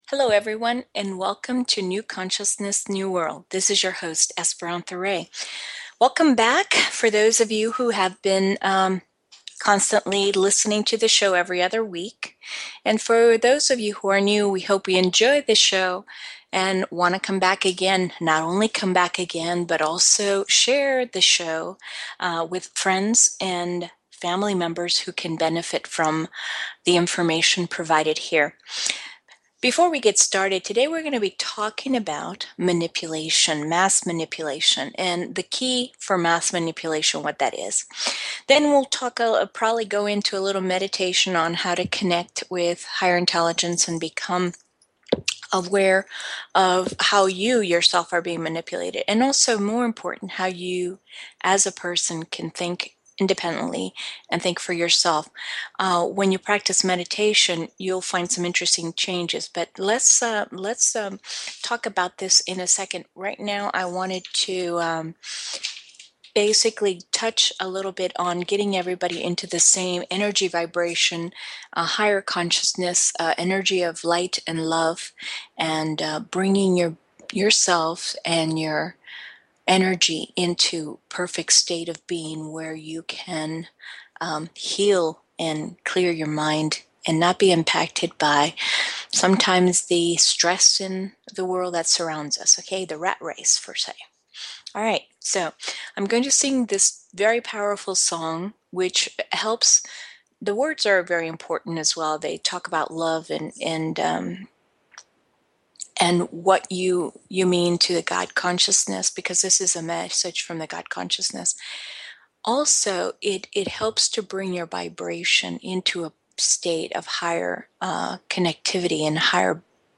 Talk Show Episode, Audio Podcast, New_Consciousness_New_World and Courtesy of BBS Radio on , show guests , about , categorized as